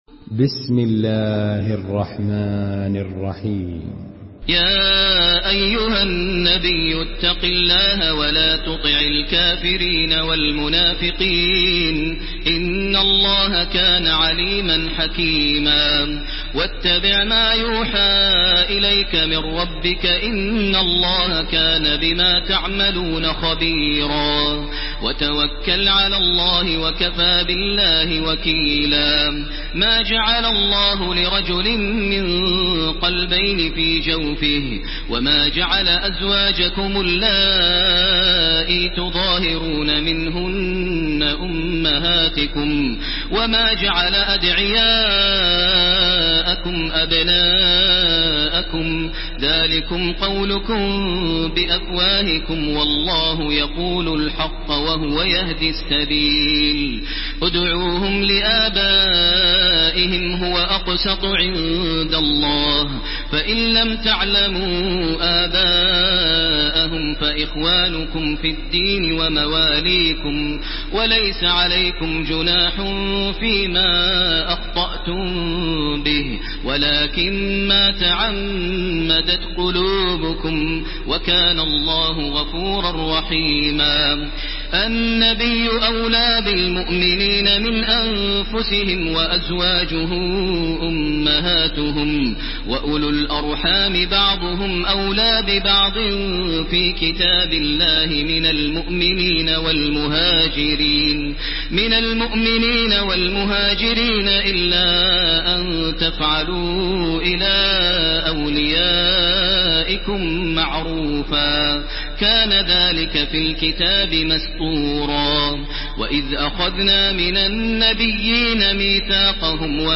Surah Al-Ahzab MP3 in the Voice of Makkah Taraweeh 1430 in Hafs Narration
Listen and download the full recitation in MP3 format via direct and fast links in multiple qualities to your mobile phone.
Murattal